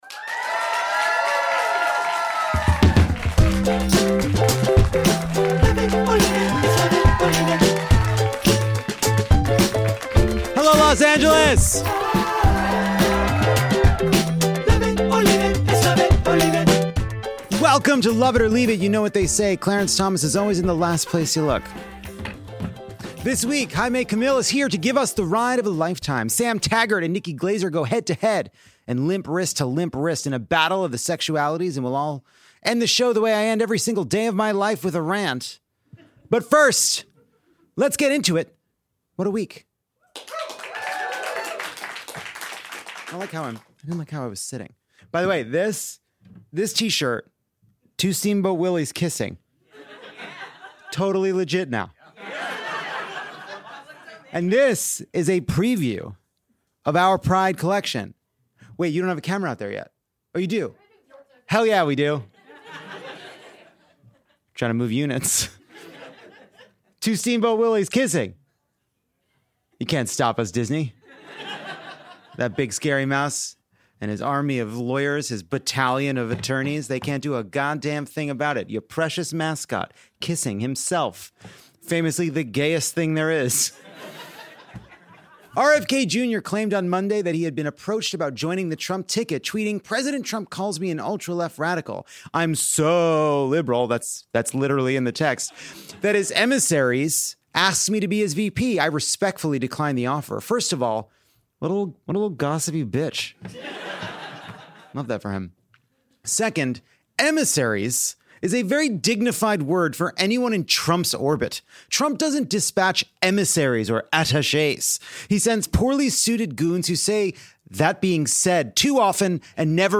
We’re live and brooding from the Tortured Podcasts Department. This week, Acapulco’s Jaime Camil pages through his motorcycle diaries.